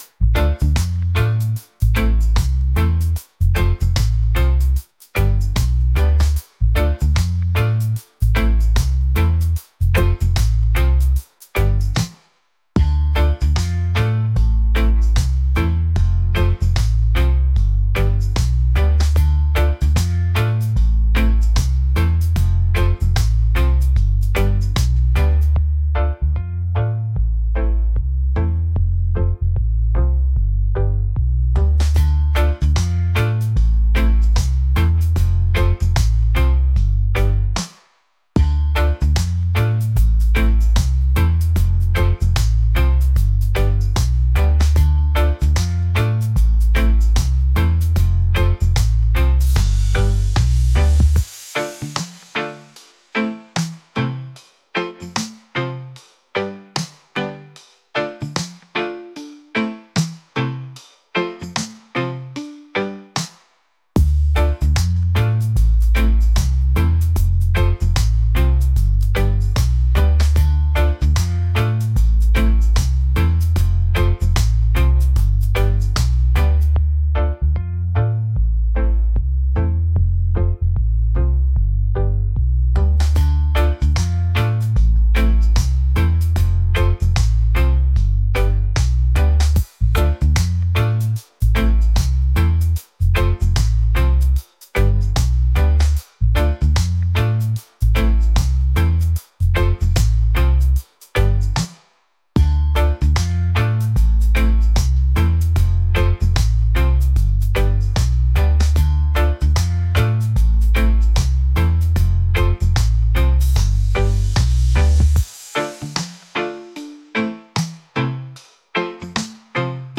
groovy | reggae | relaxed